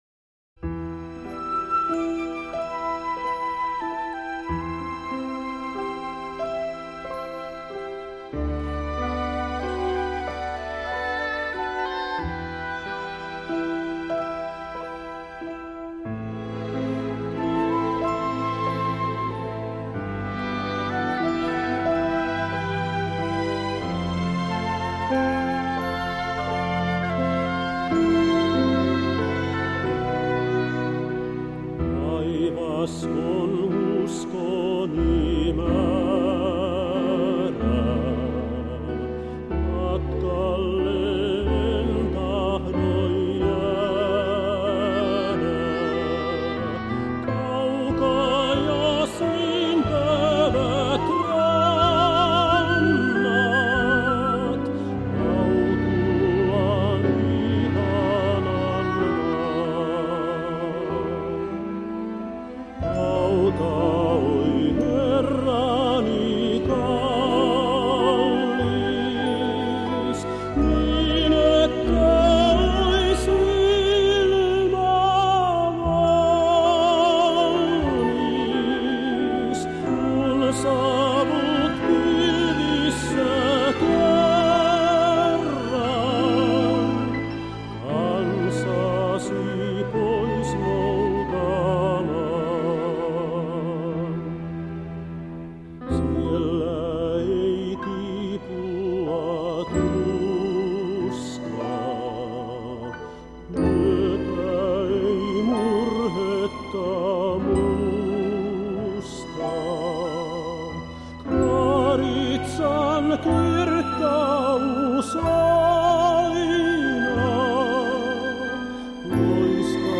Ohjelma on nauhoitettu kahdessa osassa vuosien 2025-2026 taitteessa.